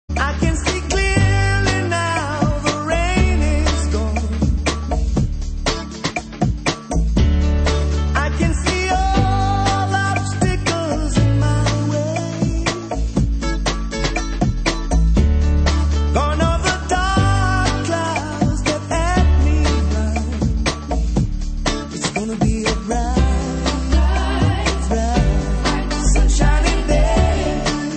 • Reggae Ringtones